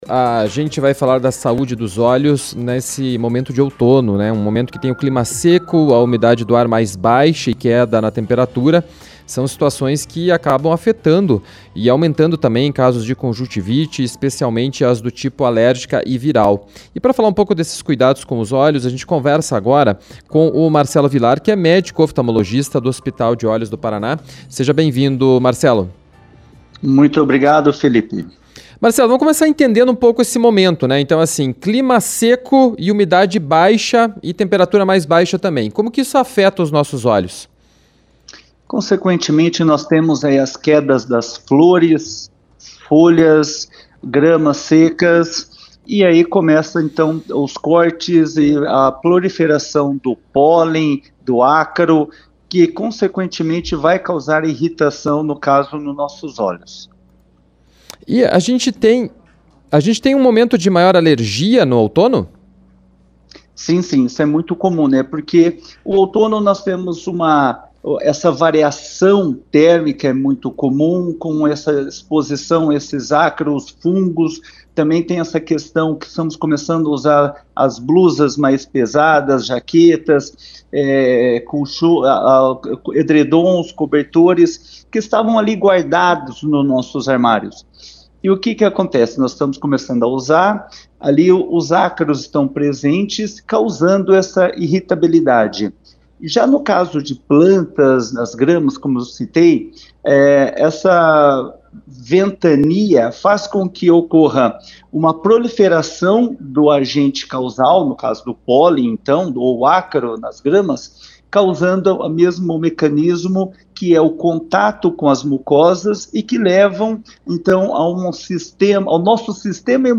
Outono: estação que demanda cuidados com os olhos – CBN Curitiba – A Rádio Que Toca Notícia